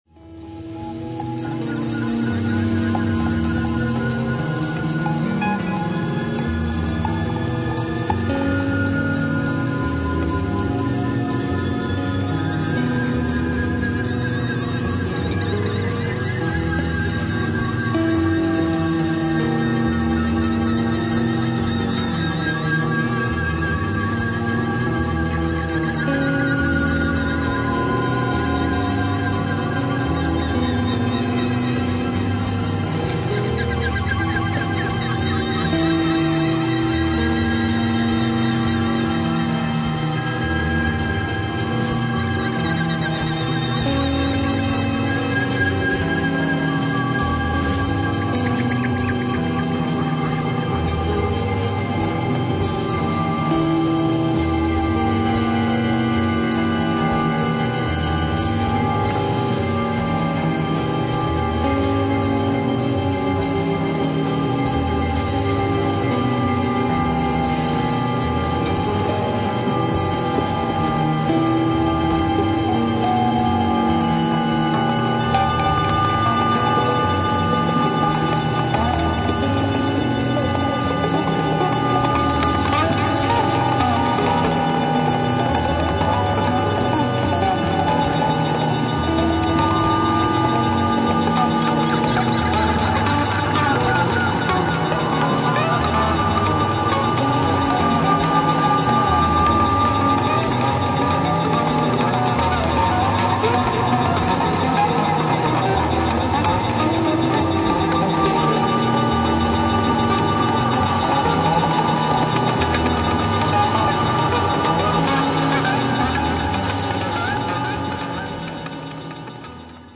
イタリアのサイケデリック・ジャムバンド